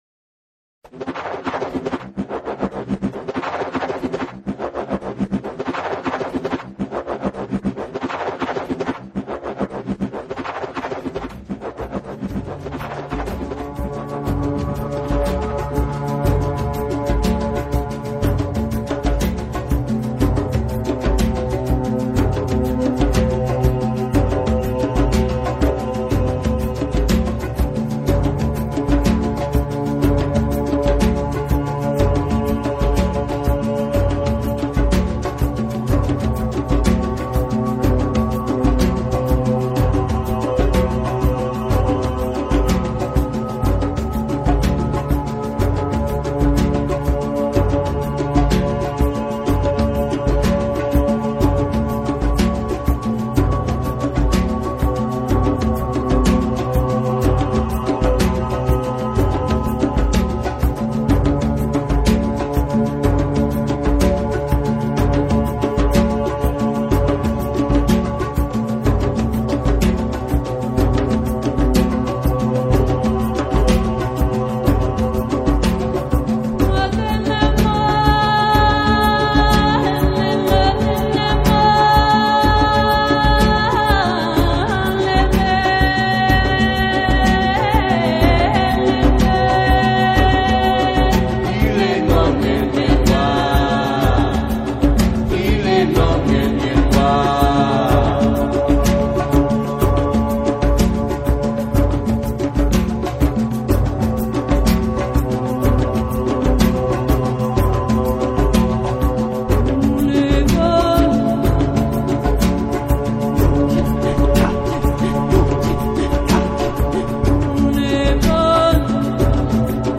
World Music, Ambient